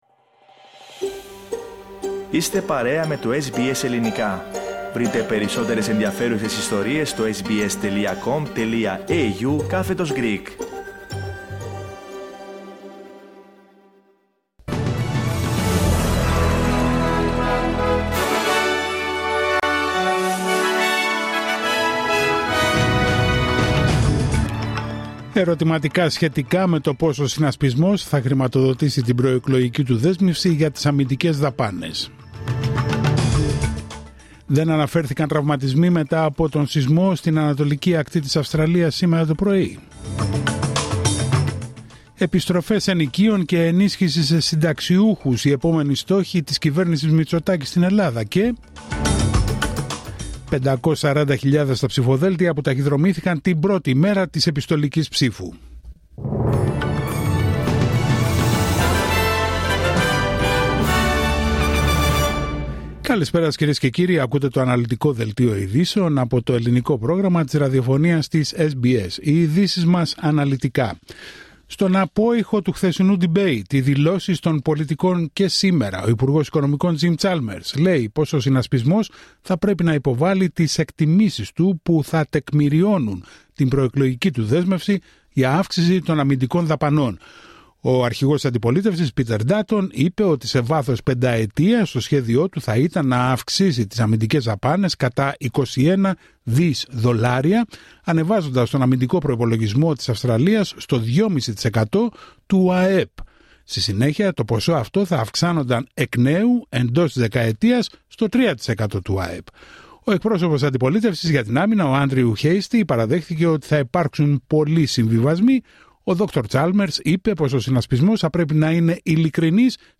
Δελτίο ειδήσεων Τετάρτη 23 Απρίλιου 2025